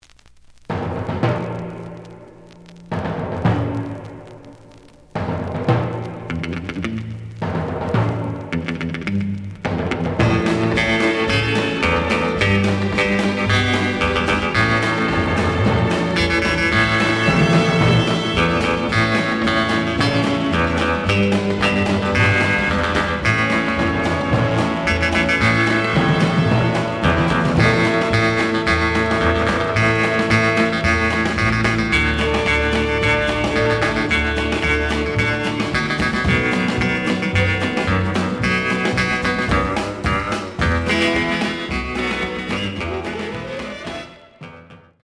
試聴は実際のレコードから録音しています。
●Format: 7 inch
●Genre: Rhythm And Blues / Rock 'n' Roll